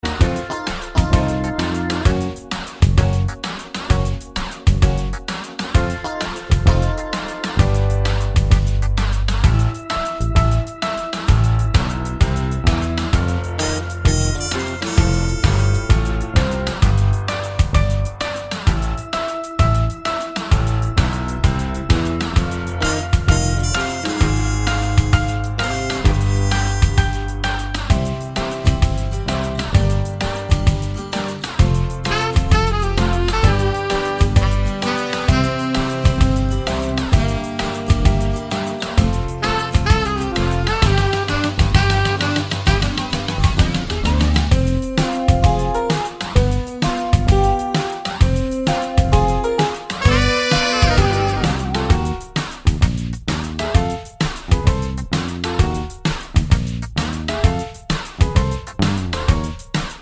no Backing Vocals Ska 3:50 Buy £1.50